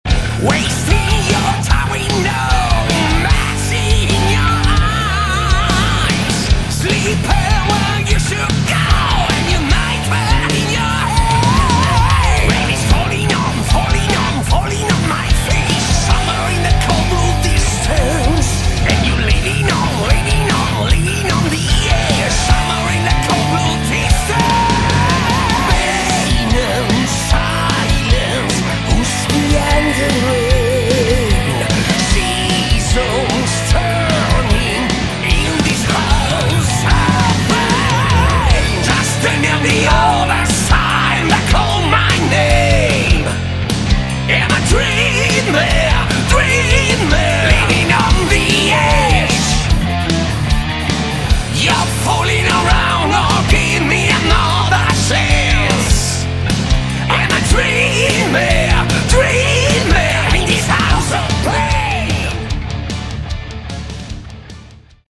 Category: Melodic/Power Metal